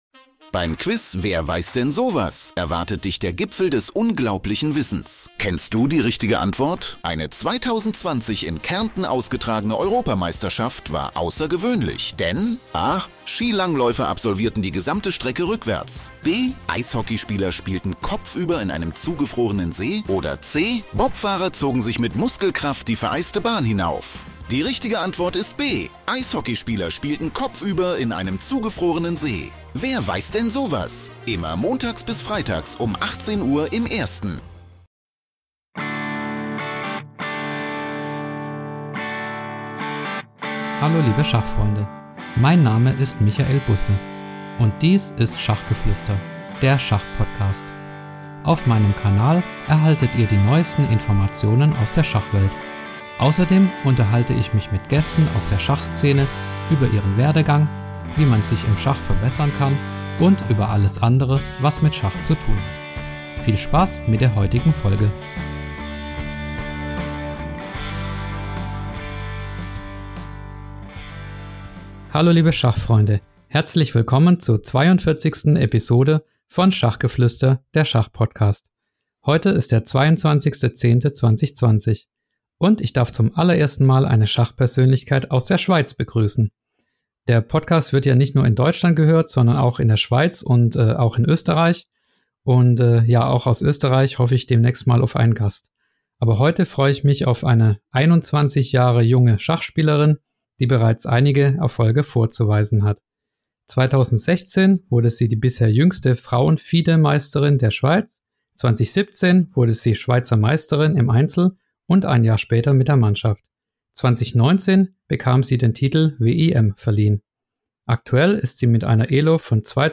Außerdem im Interview: woher kommt eigentlich der Name "Schweizer System"? Und wer sind Ihre TOP 5 der Schweizer Schachspieler aller Zeiten? Ein Gespräch mit einer bescheidenen und erfolgreichen jungen Sportlerin.